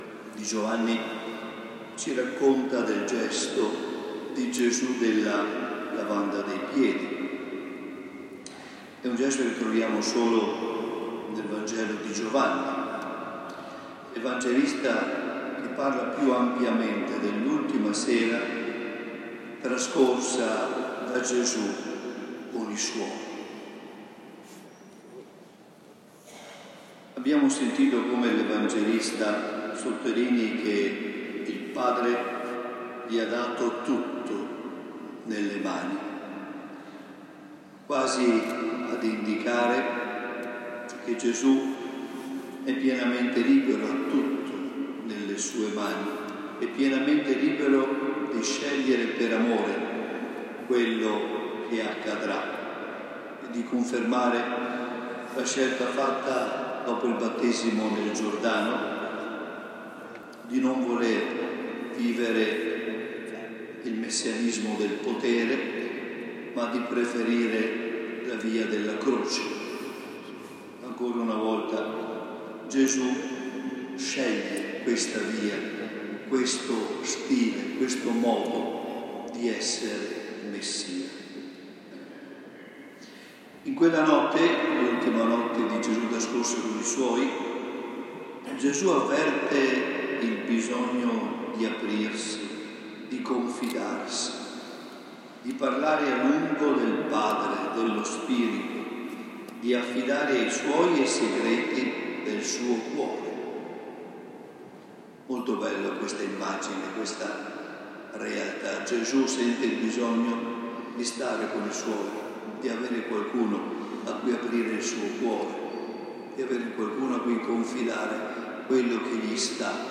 La Delegazione Liguria partecipa alla Santa Messa in Coena Domini nella Cattedrale di Genova
Nella sua omelia [